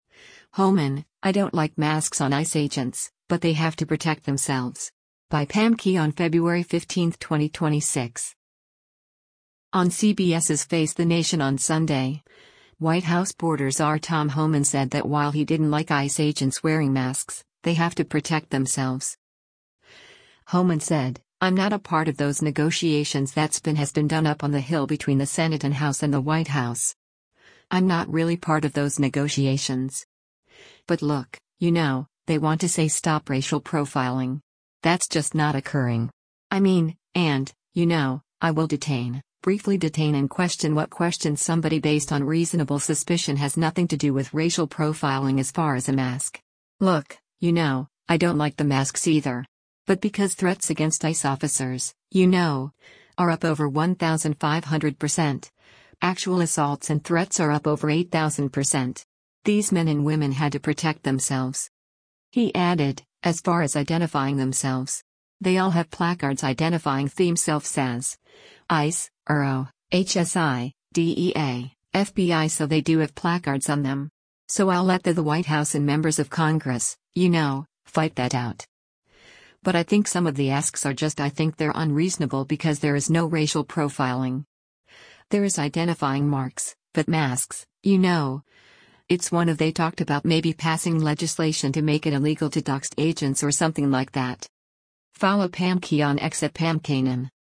On CBS’s “Face the Nation” on Sunday, White House border czar Tom Homan said that while he didn’t like ICE agents wearing masks, they “have to protect themselves.”